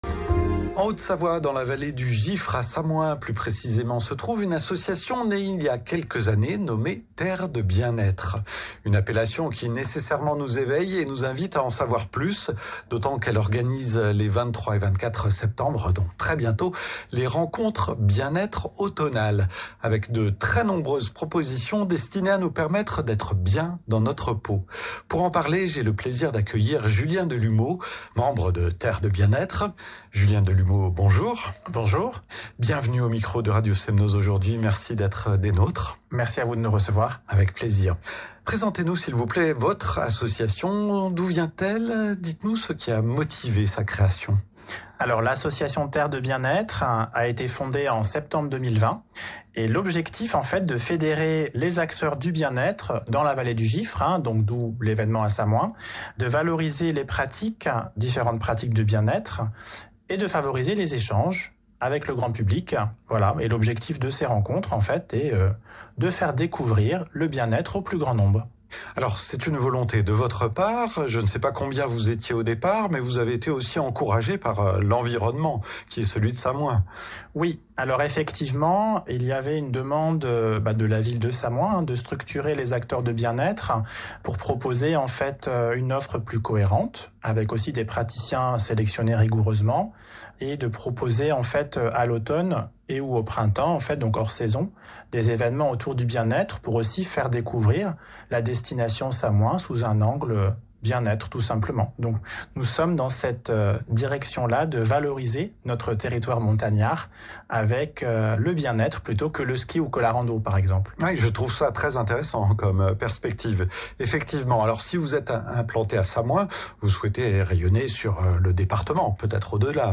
Interview - Radio Giffre